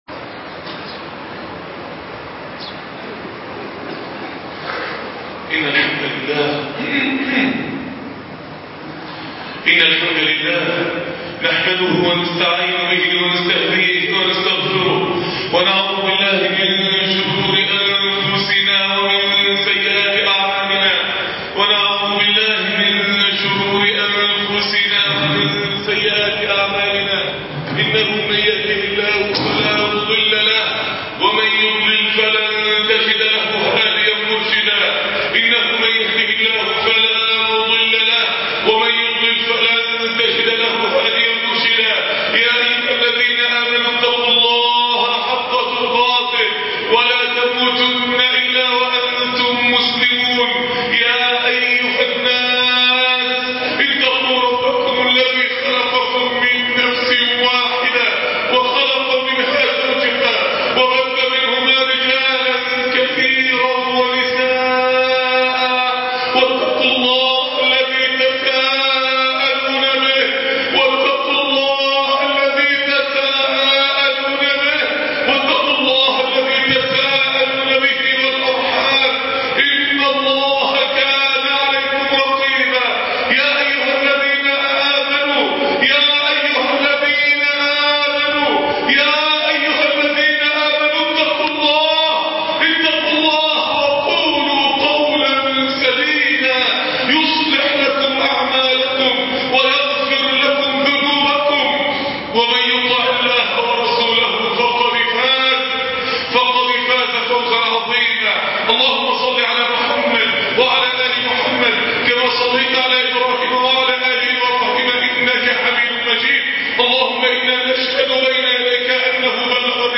ألقوا ما انتم ملقون ( خطب الجمعة